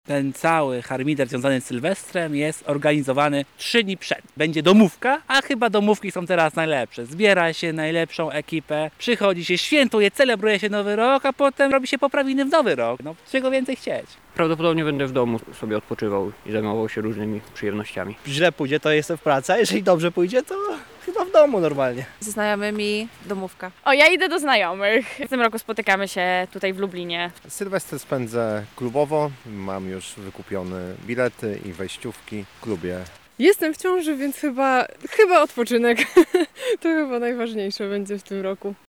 SONDA: Jakie plany na Sylwestra mają Lublinianie?
Zapytaliśmy mieszkańców Lublina, jak planują spędzić sylwestrową noc:
Sonda